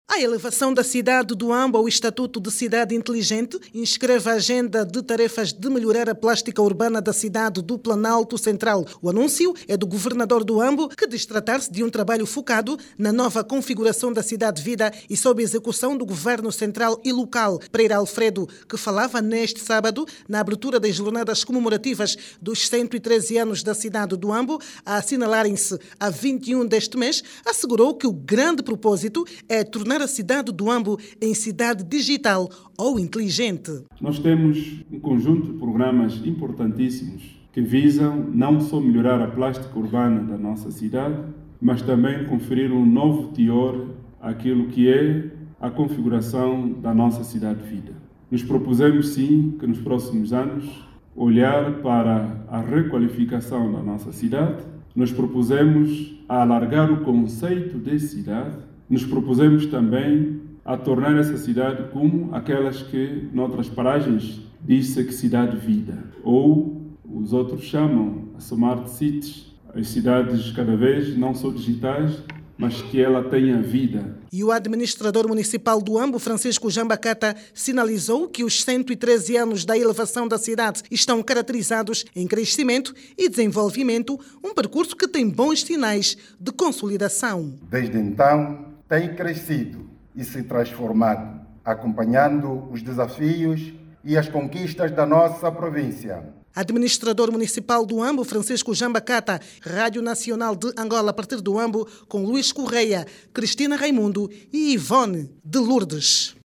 O governo do Huambo, anunciou um ambicioso plano de modernização urbana que visa transformação a Província numa referência. Durante a abertura das jornadas comemorativas dos 113 anos da cidade do Huambo, a assinalar a 21 deste mês, o governador Pereira Alfredo destacou que o projecto de requalificação urbana, vai preservar as características da cidade, ao mesmo tempo que vai corrigir deficiências estruturais acumuladas ao longo do tempo. Clique no áudio abaixo e ouça a reportagem